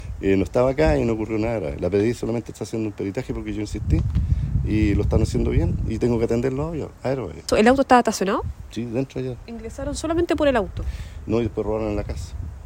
Al conversar brevemente con la víctima, aclaró que nadie de su familia resultó herido.